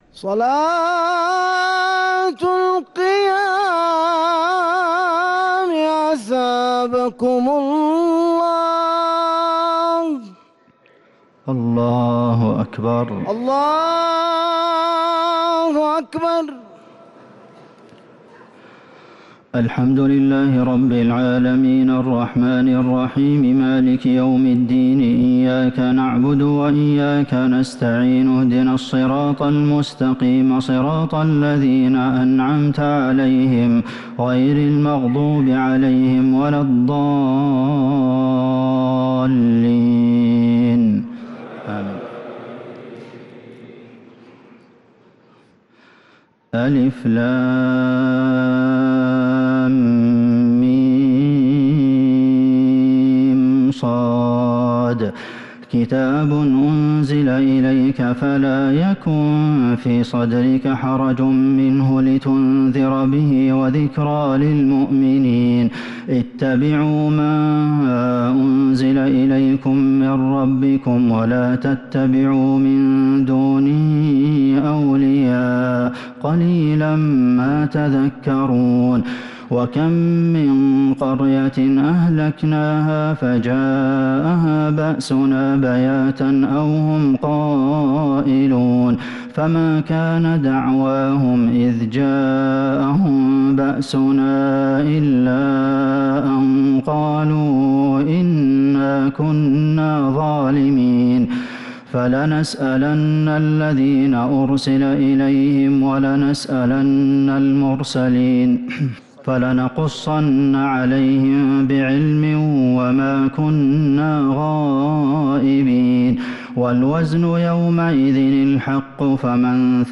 تراويح ليلة 11 رمضان 1447هـ فواتح سورة الأعراف (1-79) | Taraweeh 11th niqht Ramadan Surat Al-A’raf 1447H > تراويح الحرم النبوي عام 1447 🕌 > التراويح - تلاوات الحرمين